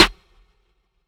Snares
RIMTRAP (2).wav